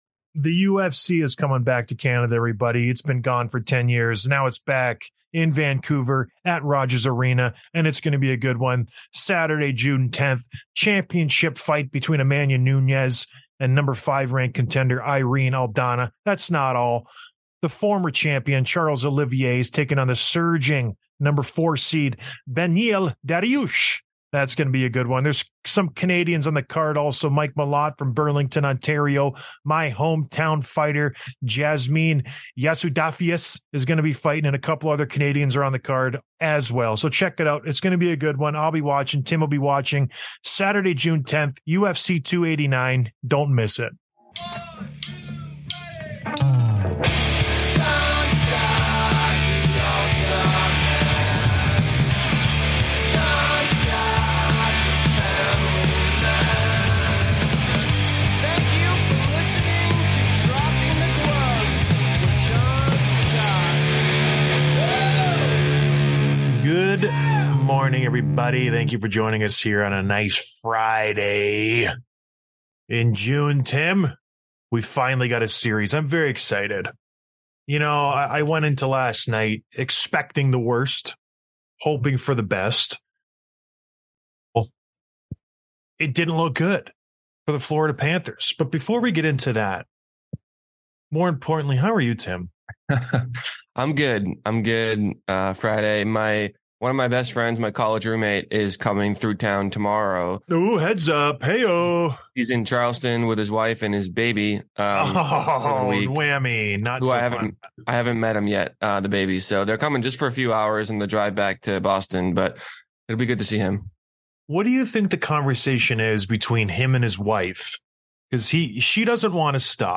Tkachuk leads Florida's late comeback once again. Rumor update on DeBrincat, PLD, Hart, and more. And listener voicemails.